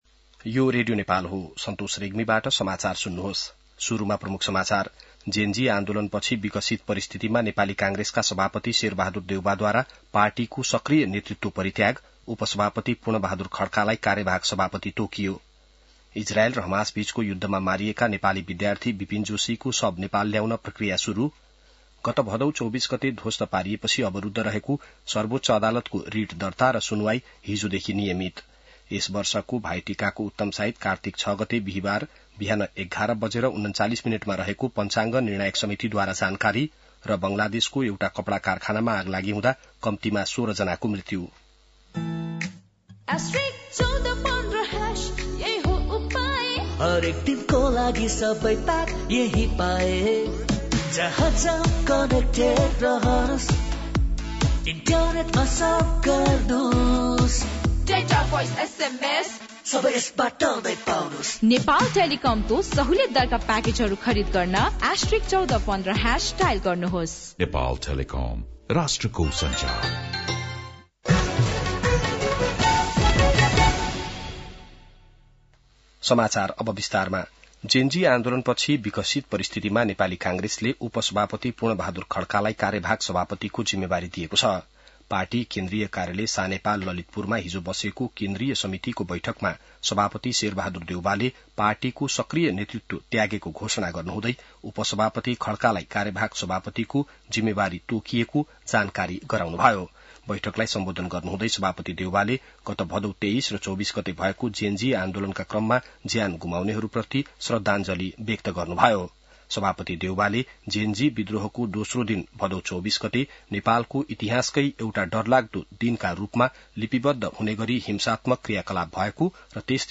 बिहान ७ बजेको नेपाली समाचार : २९ असोज , २०८२